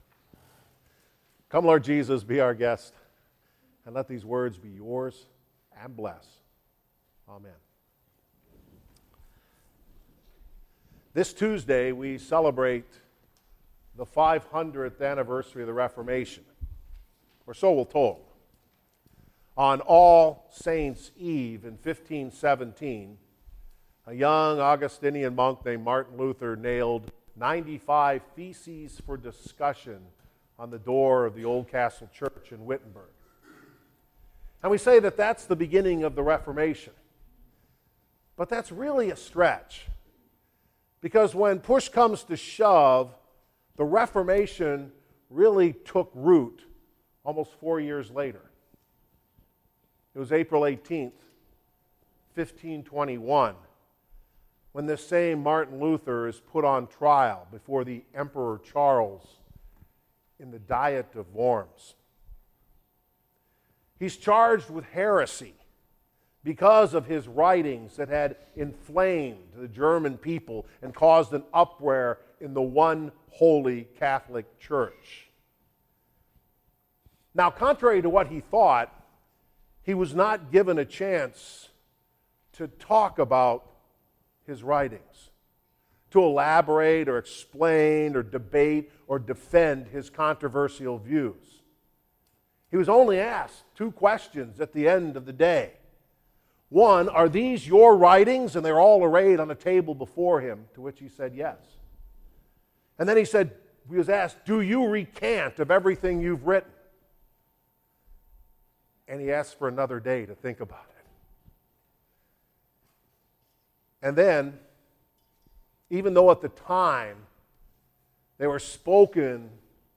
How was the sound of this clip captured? Therefore, today’s service is delineated into several sections corresponding to the Six Chief Parts of Luther’s Small Catechism, through which the fundamental doctrines of the Christian Church are taught.